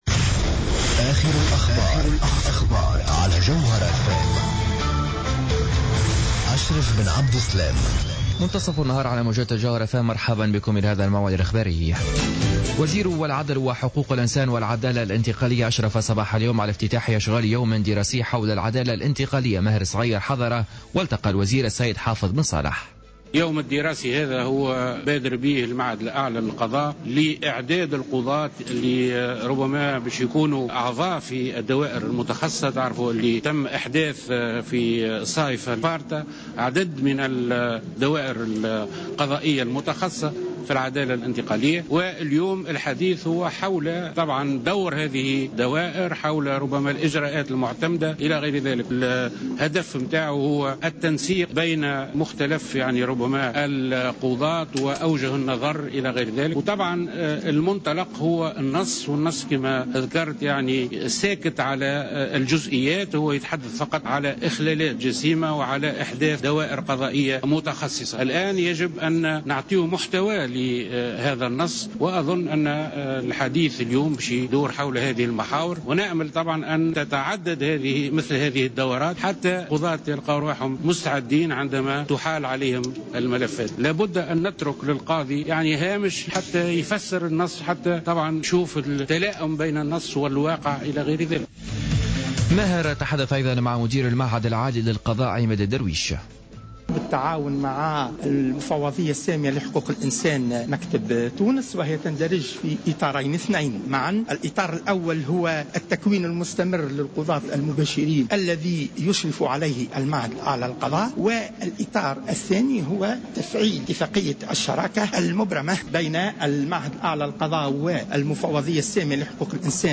نشرة أخبار منتصف النهار ليوم الجمعة 16-01-15